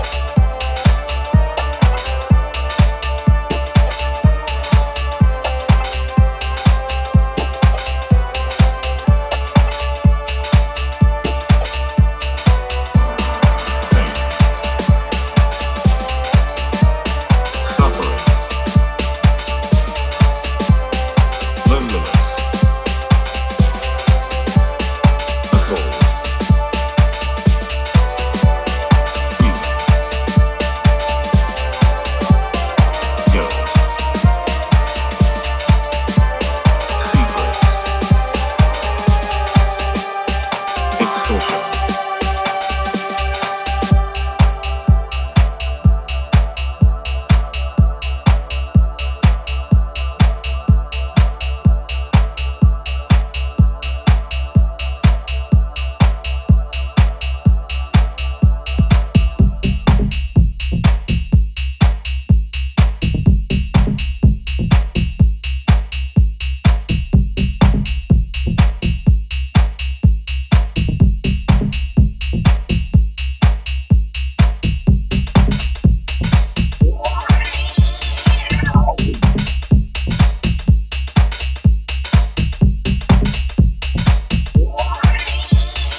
starting off with an explosive peak-time bomb